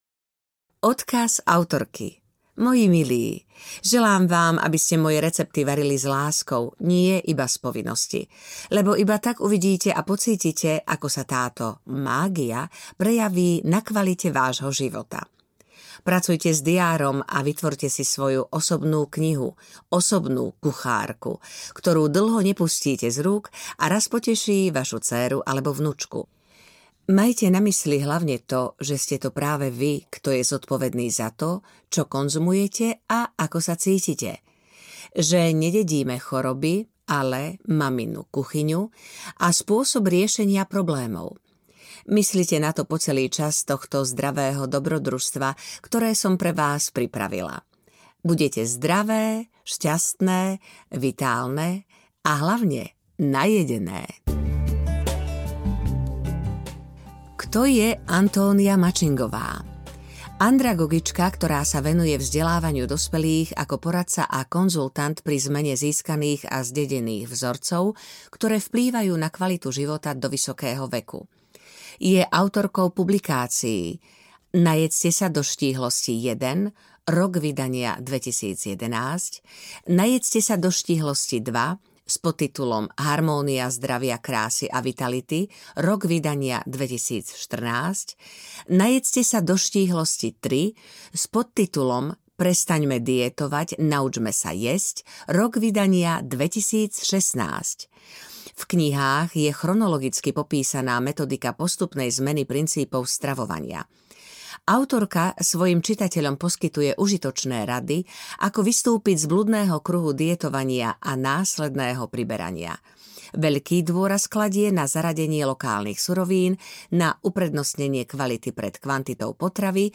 Najedzte sa do štíhlosti 1 audiokniha
Ukázka z knihy